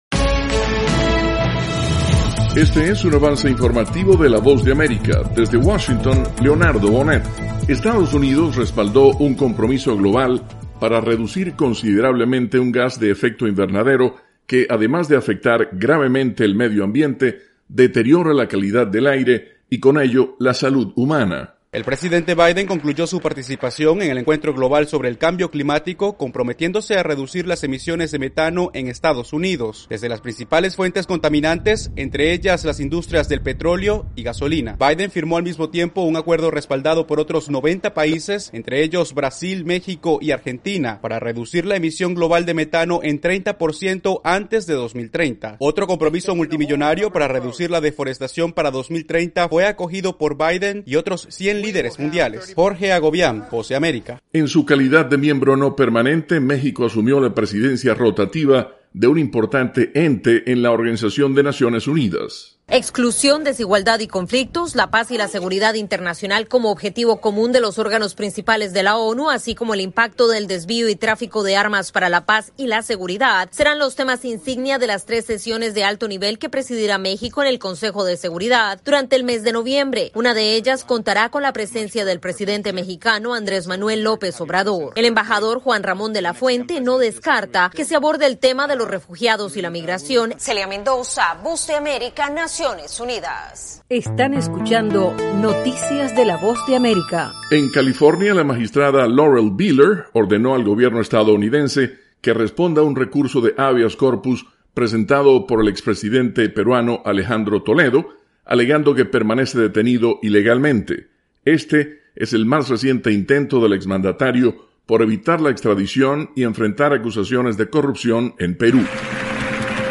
Resumen informativo con algunas de las noticias más importantes de Estados Unidos y el resto del mundo.